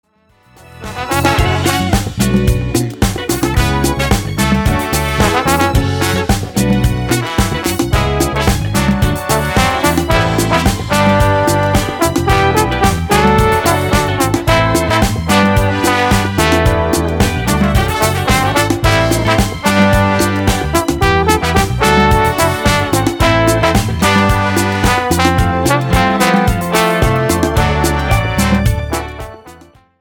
POP  (03.35)